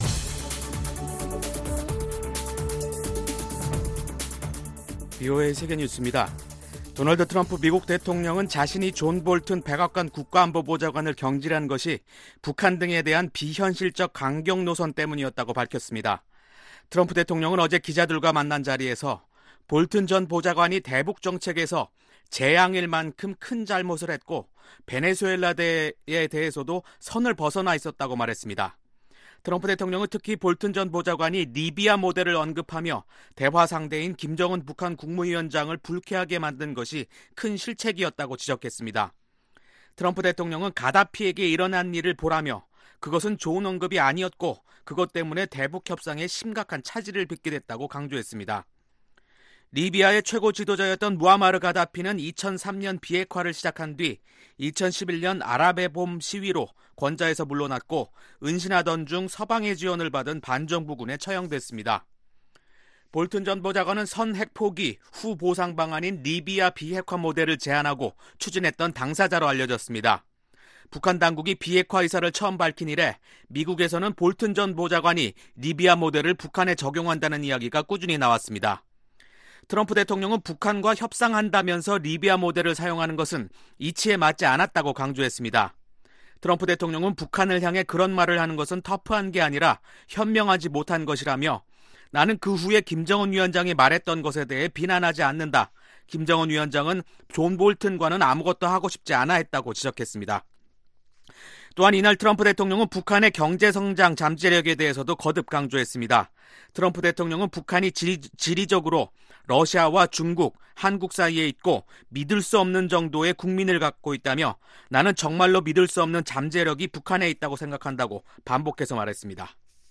VOA 한국어 간판 뉴스 프로그램 '뉴스 투데이', 2019년 9월 12일 2부 방송입니다. 트럼프 미국 대통령은 존 볼튼 전 백악관 국가 안보보좌관이 북한 문제와 관련해 ‘리비아 모델’을 언급한 것은 큰 실수였다고 지적했습니다. 미국 민주당 대통령 후보 경선자들 가운데 지지율 상위 5위에 든 후보들은 모두 김정은 위원장을 직접 만날 의향을 밝혔습니다.